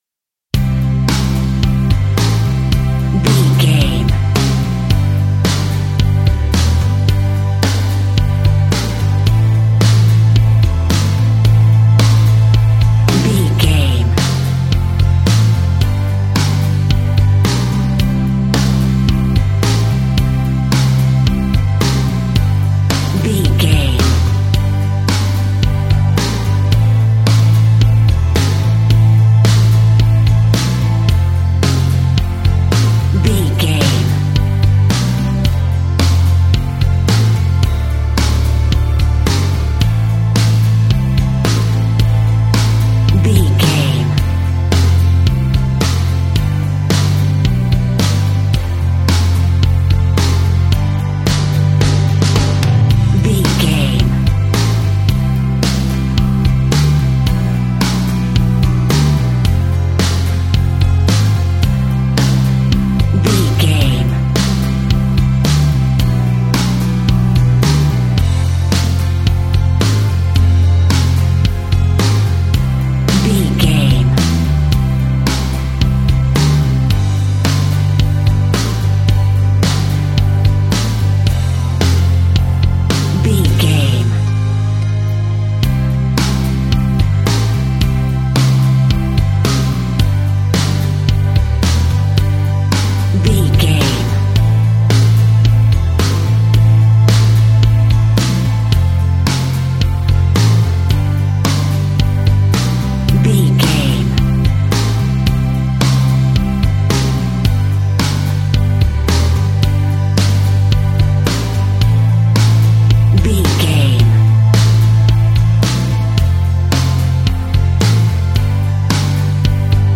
Ionian/Major
calm
melancholic
smooth
uplifting
electric guitar
bass guitar
drums
pop rock
indie pop
organ